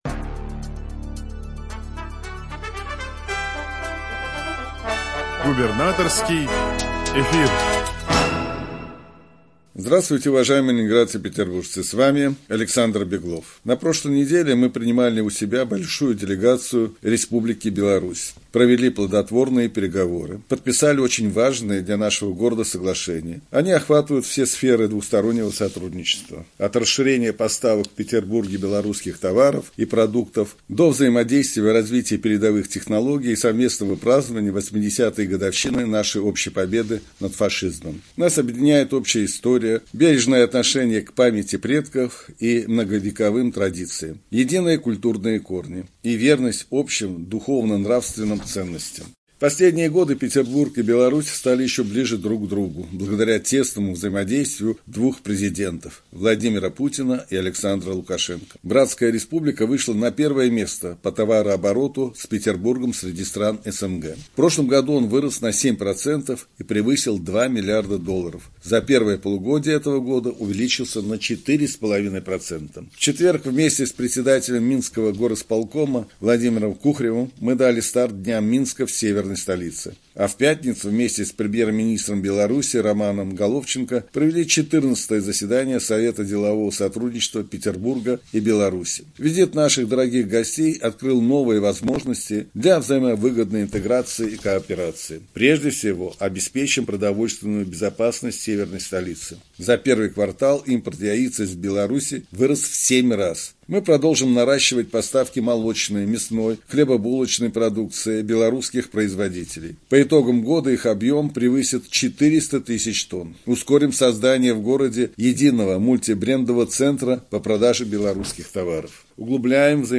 Радиообращение – 28 октября 2024 года